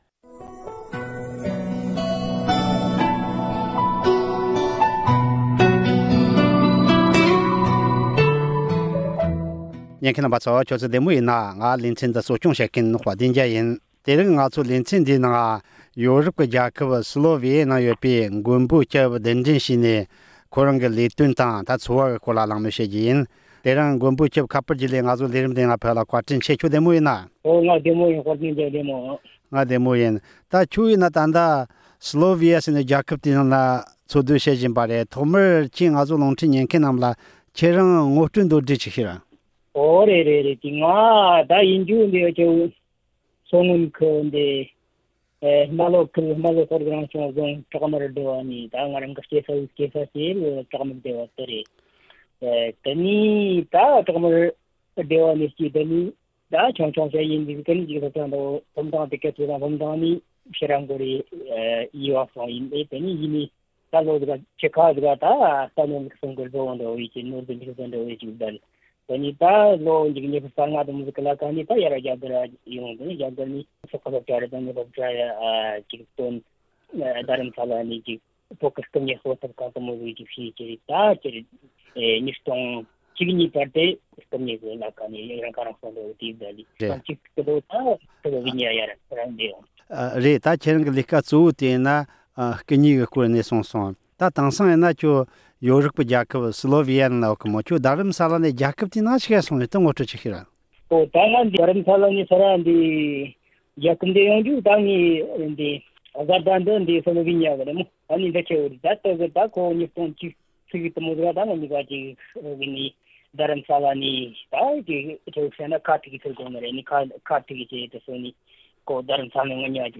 ཁོང་གི་འཚོ་བའི་སྐོར་གླེང་མོལ་ཞུས་པ།